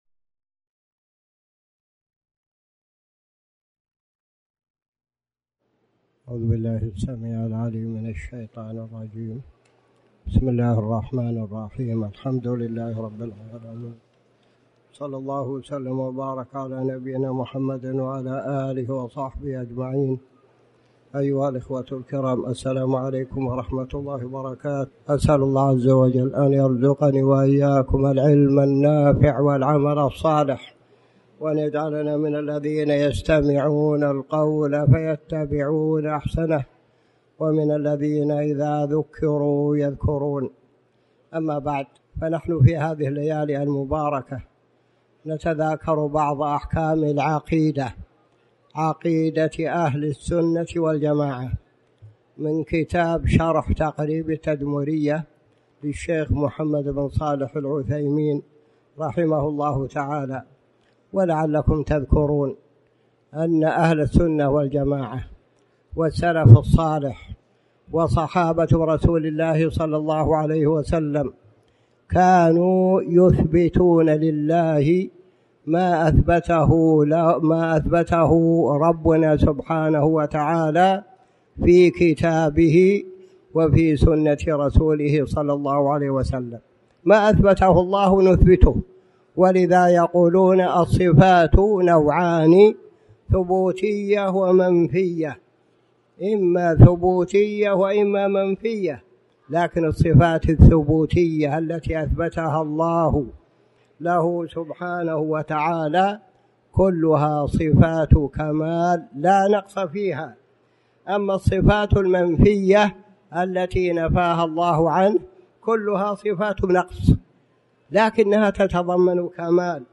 تاريخ النشر ٤ ذو القعدة ١٤٣٩ هـ المكان: المسجد الحرام الشيخ